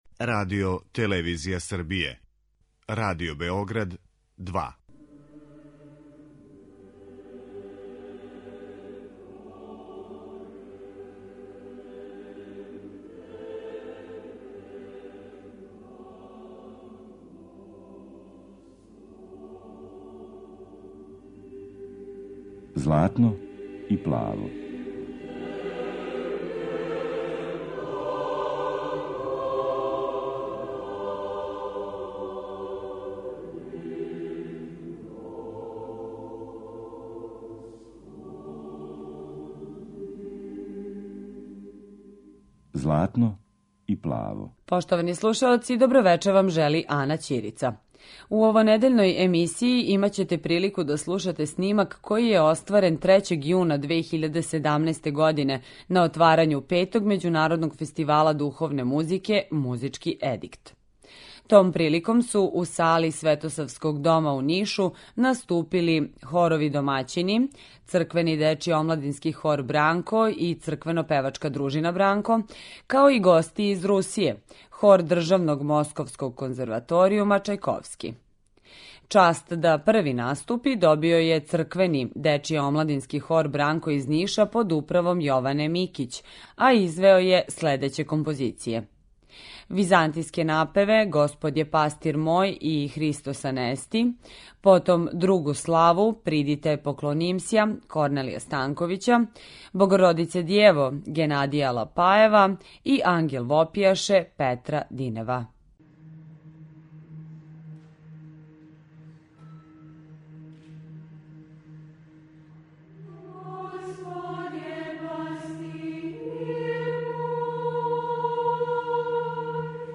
Међународни фестивал хорске духовне музике у Нишу
Емитоваћемо снимак који је остварен 3. 6. 2017. године, на отварању Петог Музичког едикта, када су наступила и наша два вокална састава